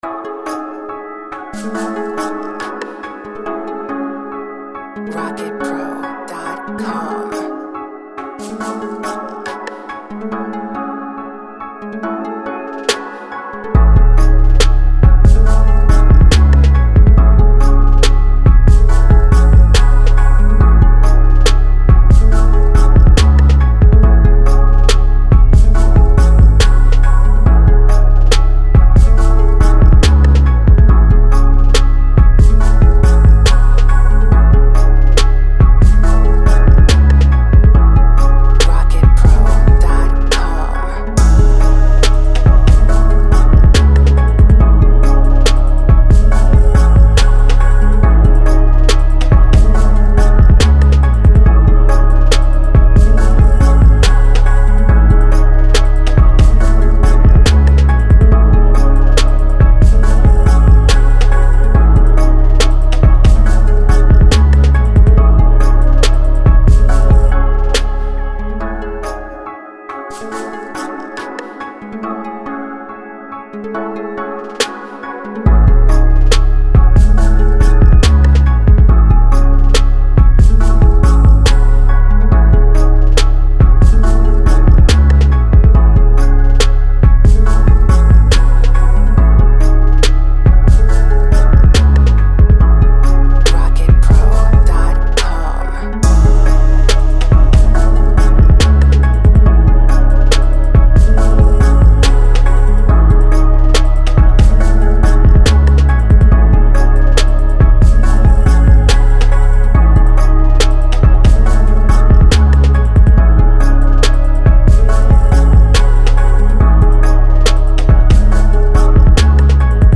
140 BPM.